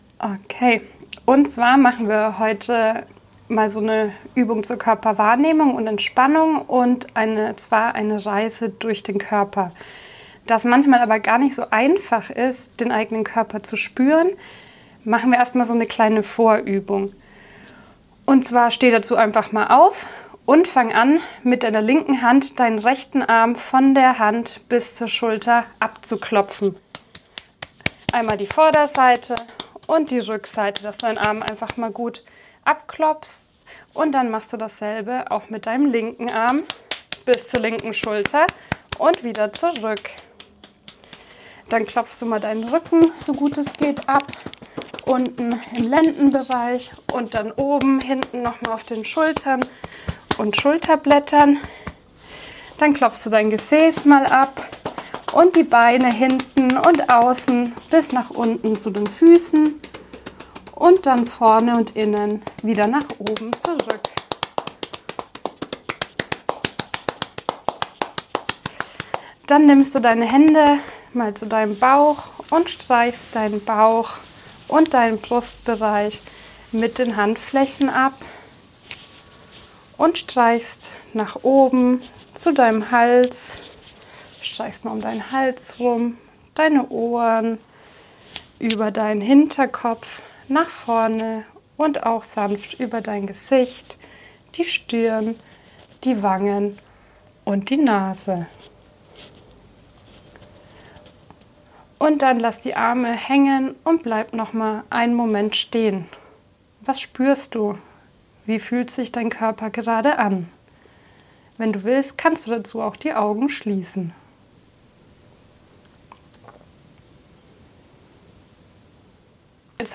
Ohne Intro, Vortext und weiteres Drumherum ... könnt Ihr einfach direkt mit der Übung starten und entspannen.